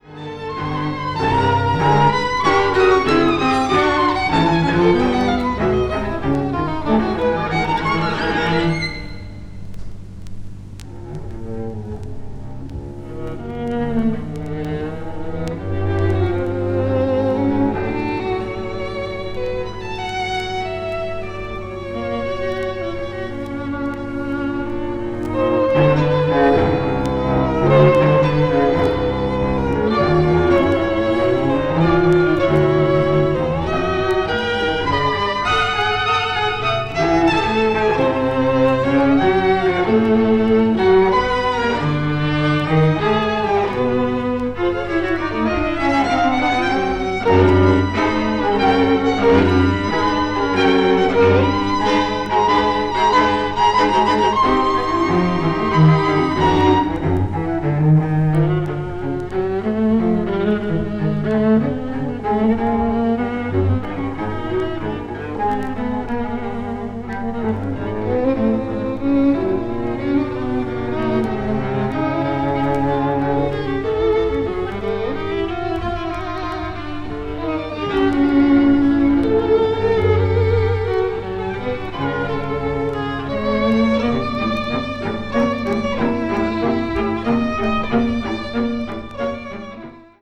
20th century   avant-garde   contemporary   post modern